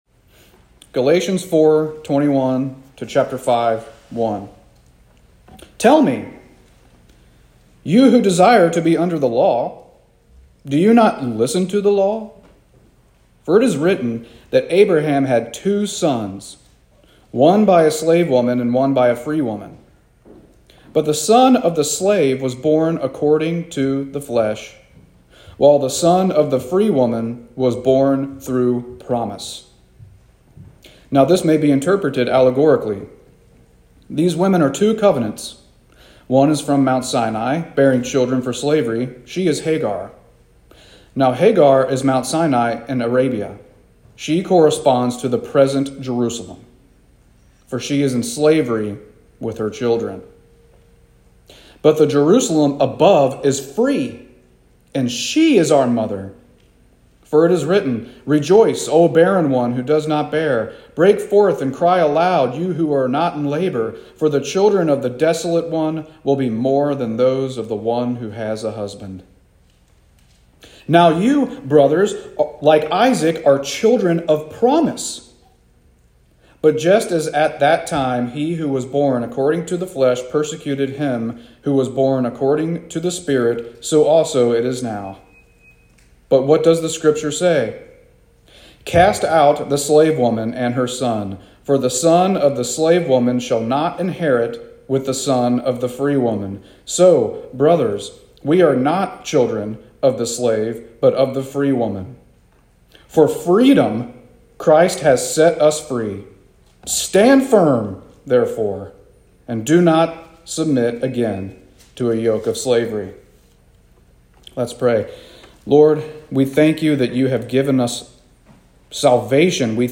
Sermons | Monterey Baptist Church